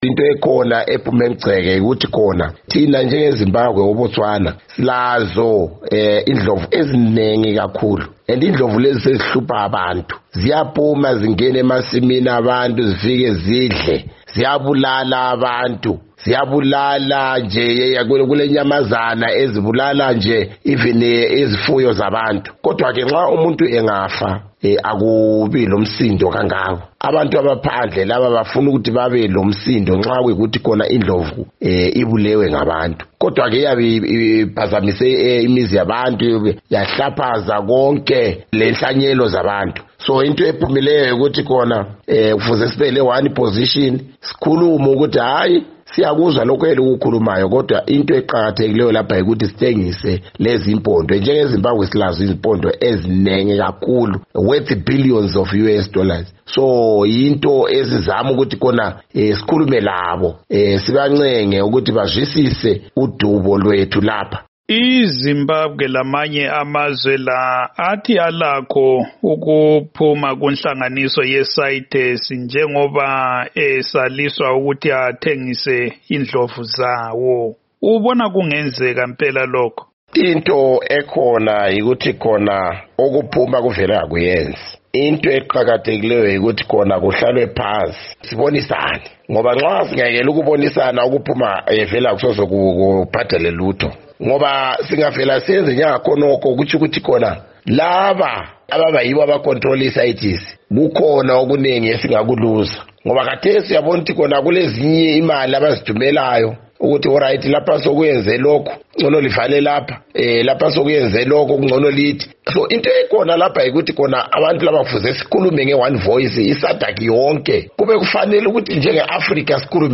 Ingxoxo loMnu Peter Moyo